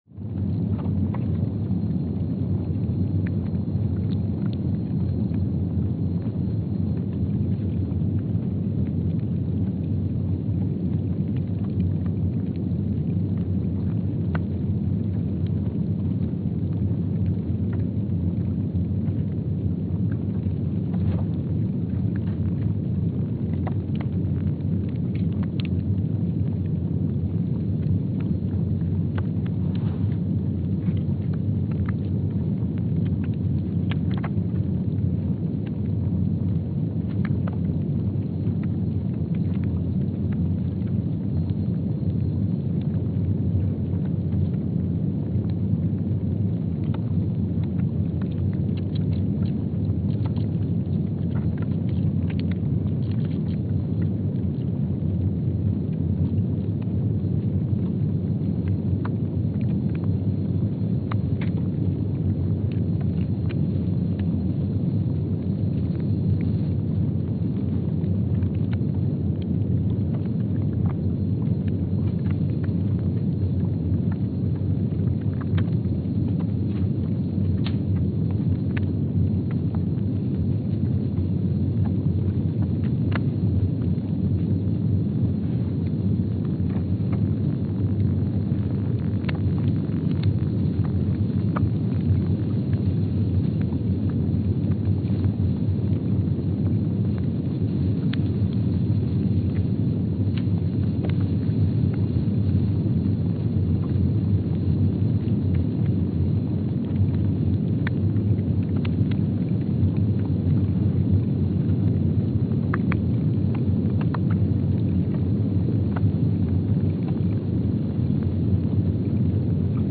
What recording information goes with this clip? Station : PMSA (network: IRIS/USGS) at Palmer Station, Antarctica Sensor : STS-1VBB_w/E300 Speedup : ×500 (transposed up about 9 octaves) Loop duration (audio) : 05:45 (stereo) Gain correction : 25dB SoX post-processing : highpass -2 90 highpass -2 90